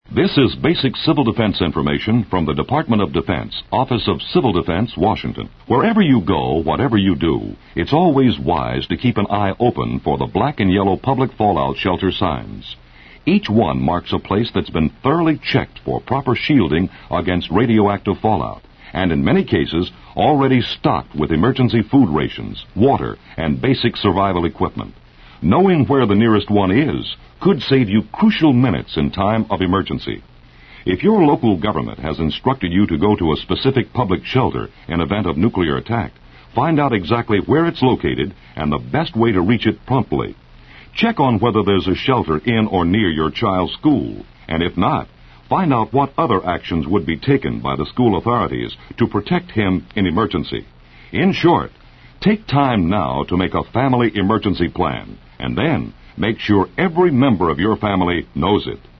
These radio spots were taken from the In Time of Emergency Radio kit #1 released by the Office of Civil Defense in 1968.